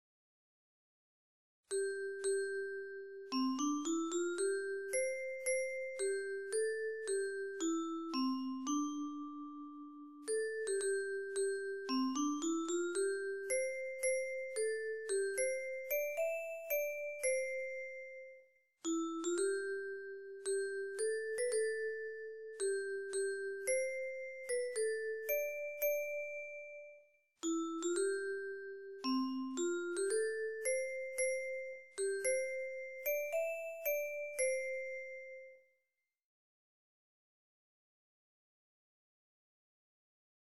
メロディーもお聴きくださいね
「鉄琴バージョン」